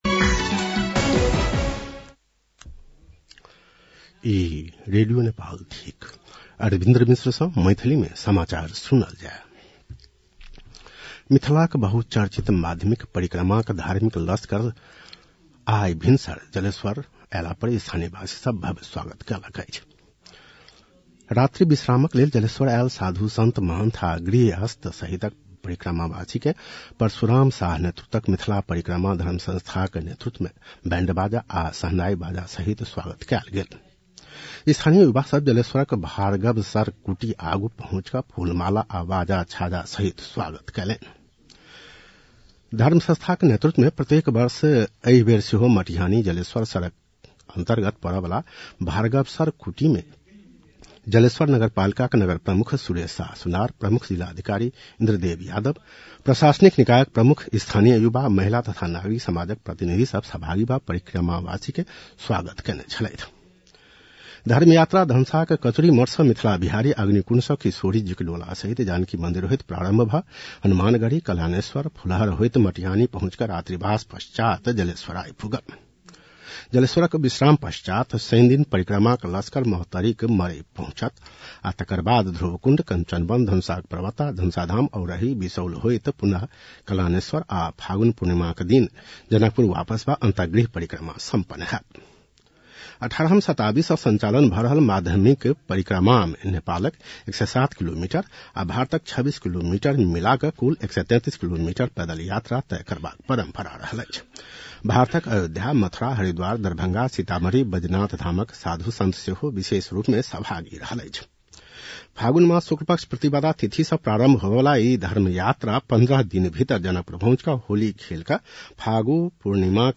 मैथिली भाषामा समाचार : ८ फागुन , २०८२
Maithali-news-11-08.mp3